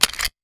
weapon_foley_pickup_05.wav